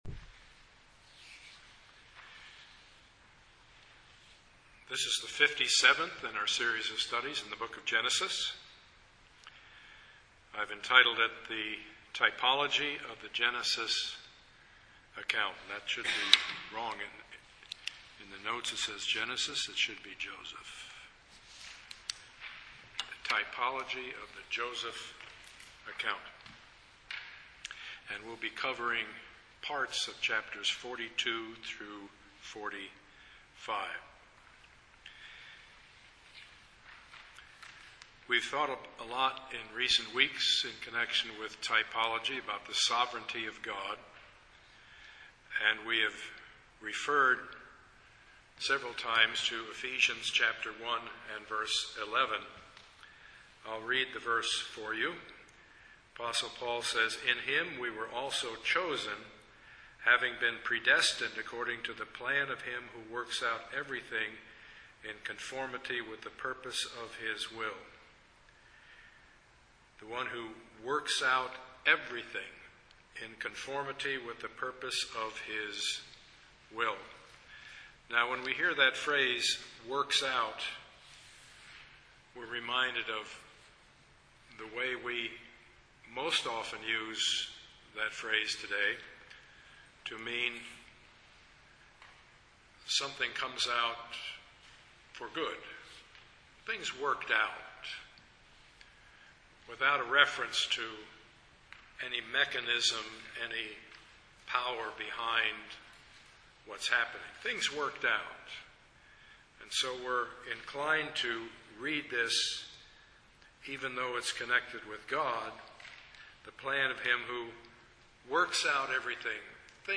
Passage: Genesis 42-45 Service Type: Sunday morning